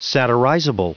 Prononciation du mot satirizable en anglais (fichier audio)
Prononciation du mot : satirizable